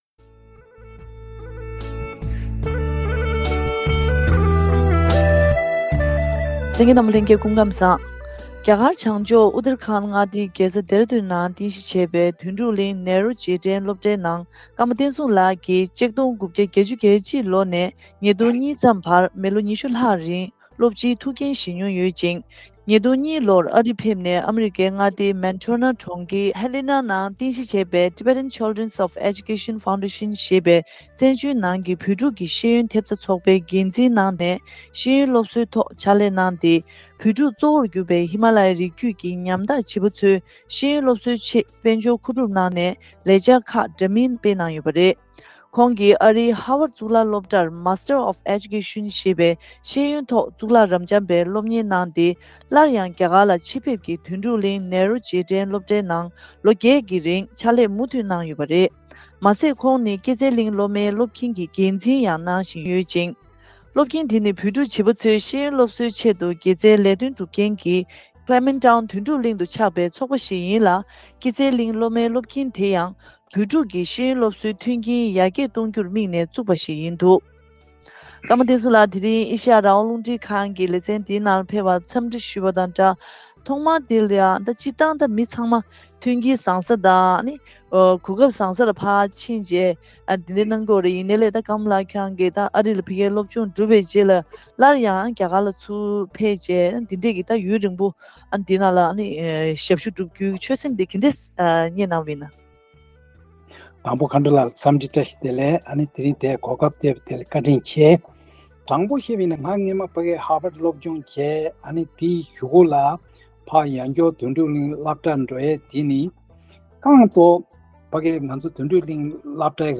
བཀའ་དྲི་ཞུས་པ་ཞིག་གསན་ གནང་གི་རེད།།